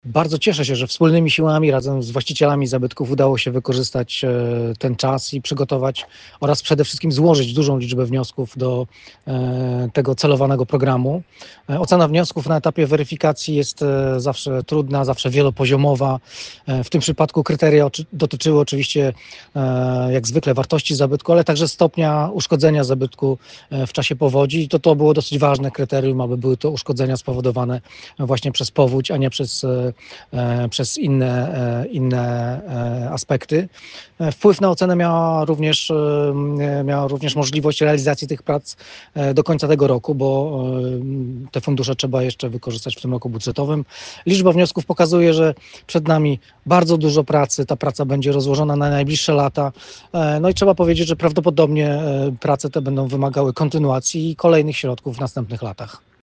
– Cieszę się, że wspólnymi siłami z właścicielami zabytków udało się wykorzystać ten czas,  przygotować i złożyć dużą liczbę wniosków do tego programu – podkreśla Daniel Gibski, Dolnośląski Wojewódzki Konserwator Zabytków.